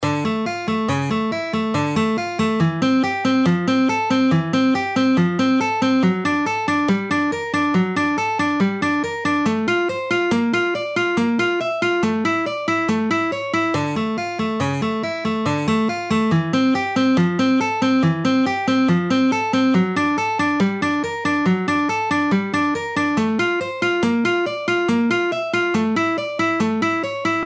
Here is exercise 2 in half speed:
Half-Speed-Alternate-Picking-Exercises-2-1.mp3